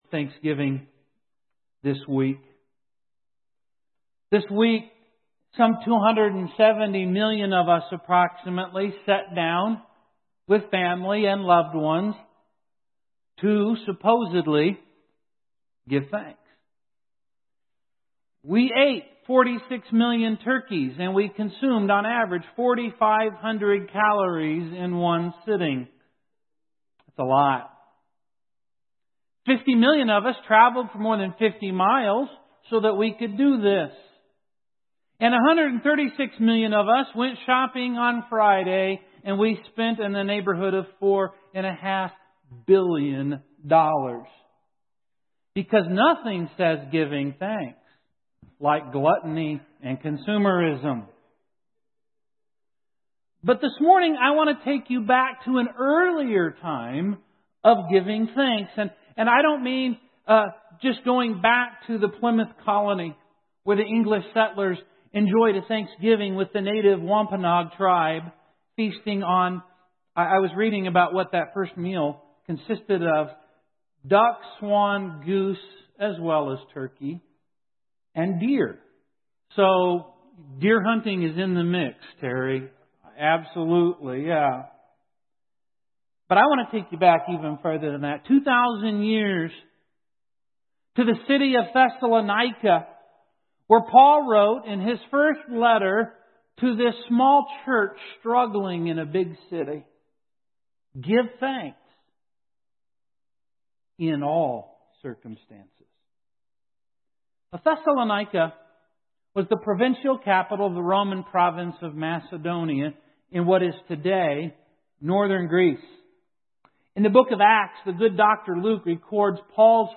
How can we give thanks in the varied circumstances of our lives. This sermon will help you get the perspective you need to give thanks in all circumstances.